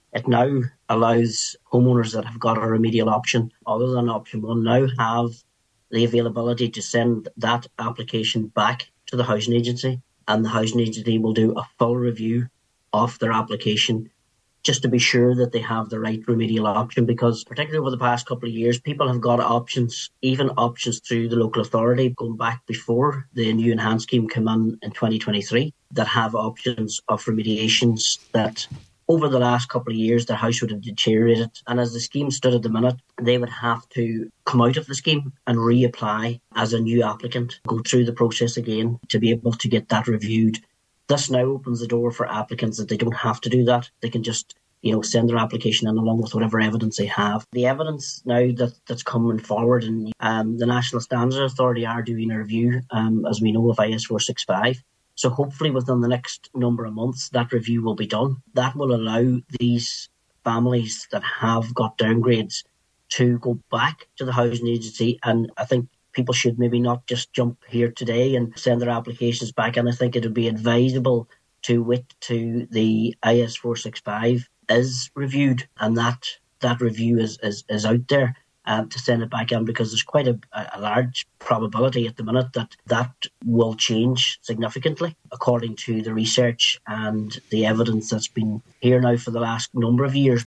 Chair of Donegal County Council’s Defective Concrete Grant Committee Councillor Martin McDermott believes when the scientific evidence is taken into consideration along with the review of IS465, there will be significant changes: